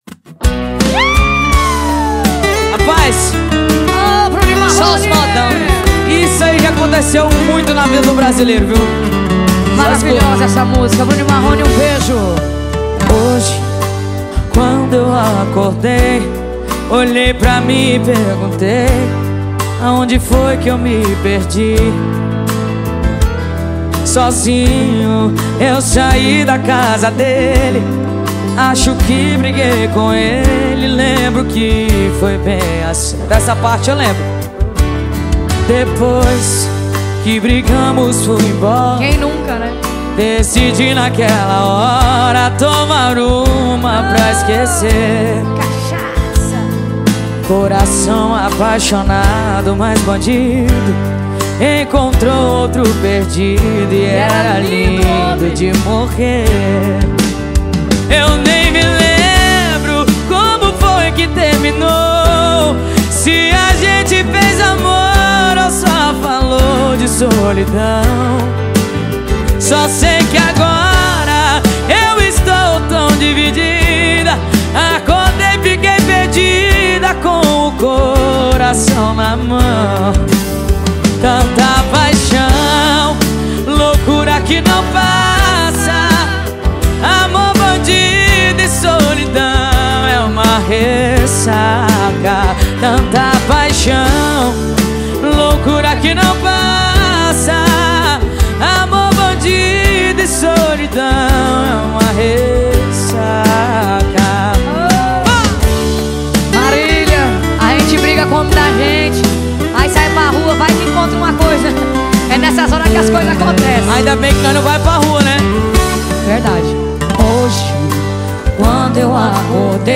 brega romantico